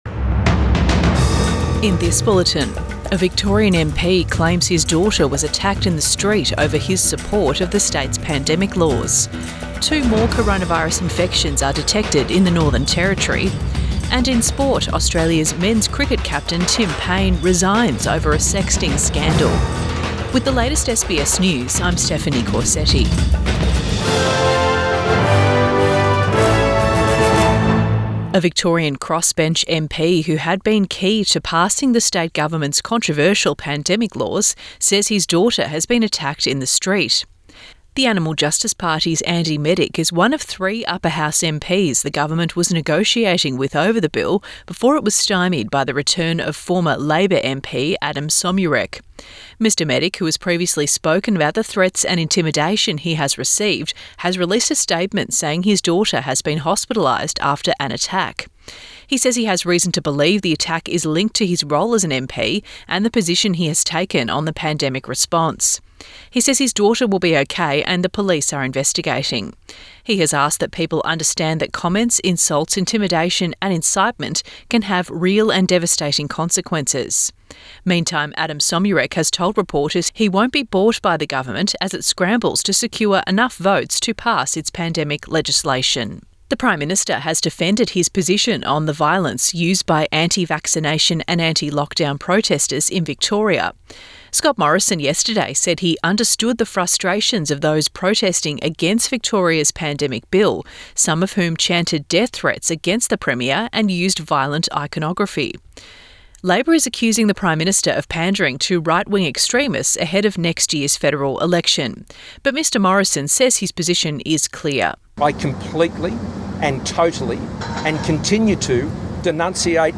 PM bulletin 19 November 2021